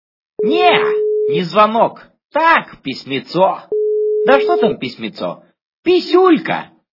» Звуки » звуки для СМС » Звук для СМС - Не не звонок, так писемцо...
При прослушивании Звук для СМС - Не не звонок, так писемцо... качество понижено и присутствуют гудки.